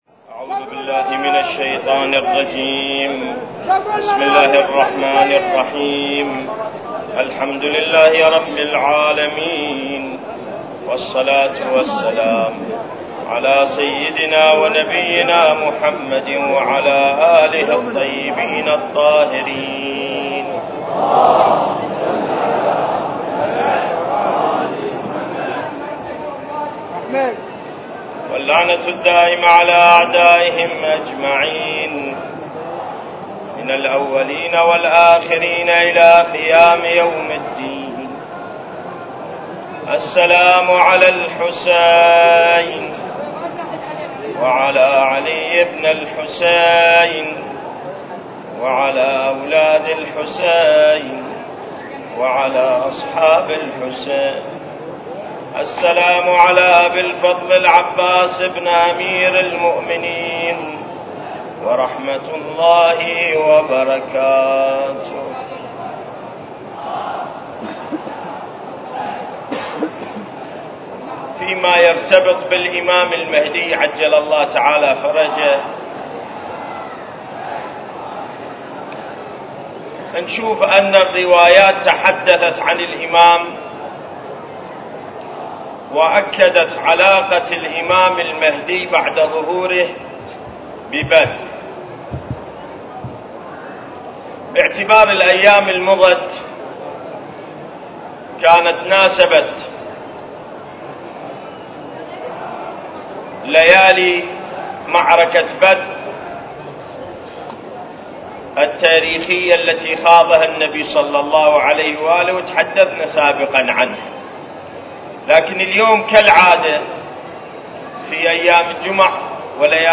المكان: العتبة الحسينية المقدسة